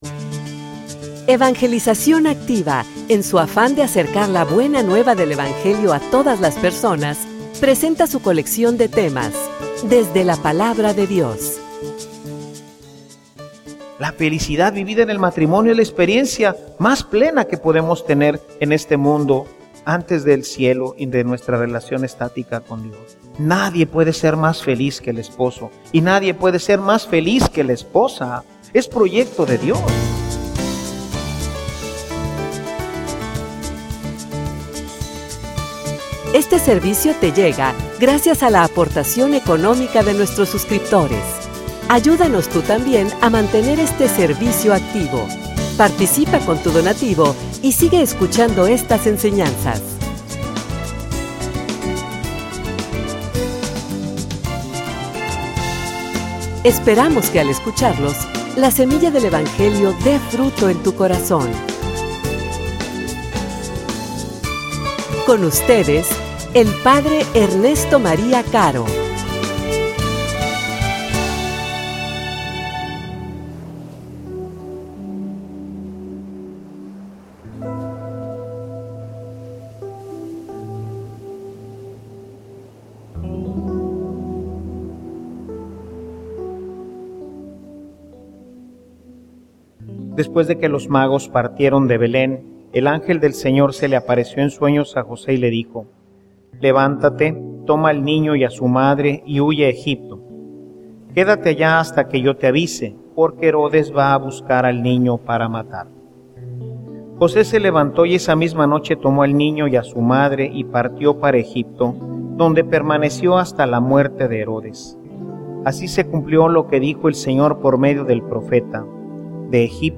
homilia_Por_que_fracasa_un_matrimonio.mp3